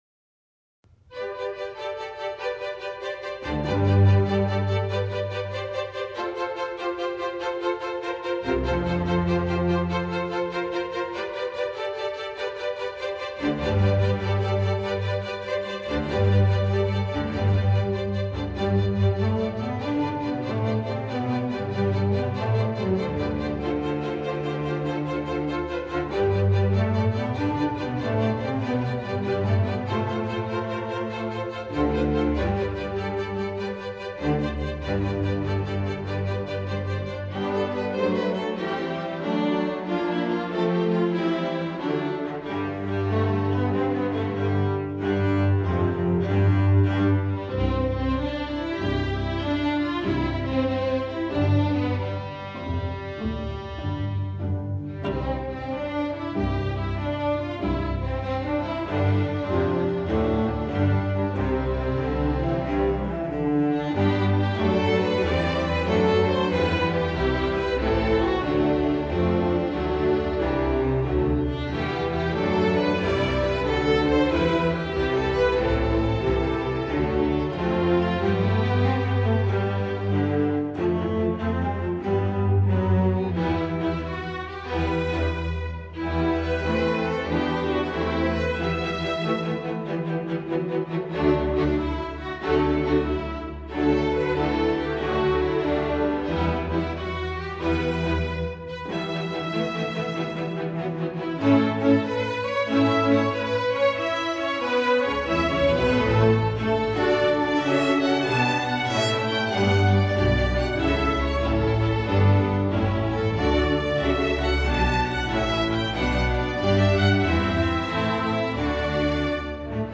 Instrumental Orchestra String Orchestra
English Carol
String Orchestra